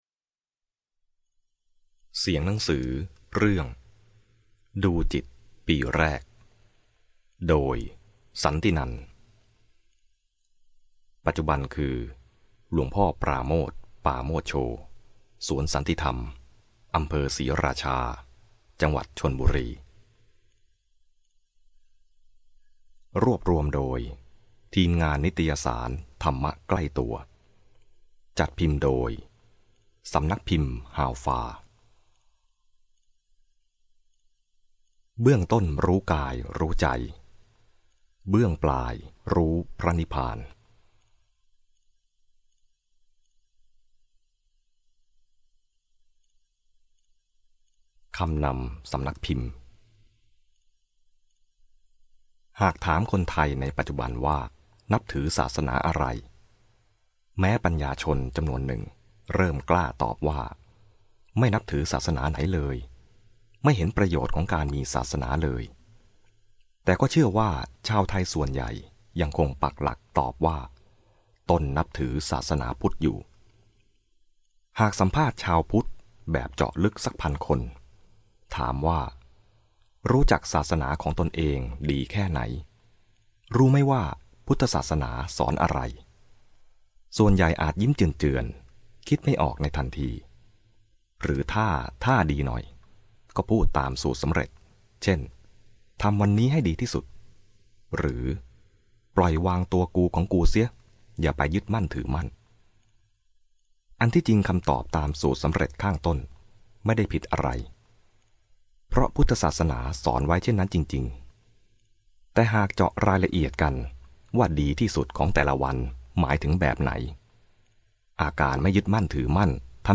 คราวนี้ผมขอเป็นส่วนหนึ่ง (ในส่วนที่พอจะทำได้นะครับ) ด้วยการให้โหลดไฟล์เสียงอ่านหนังสือ "ดูจิตปีแรก" ณ พื้นที่แห่งนี้อีก ๑ แห่งครับ